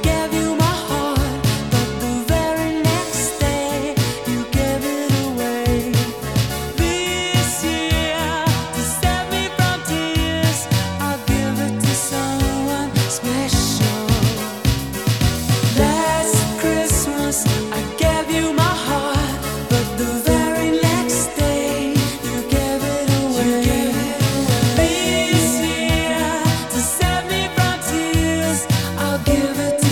• Holiday
pop duo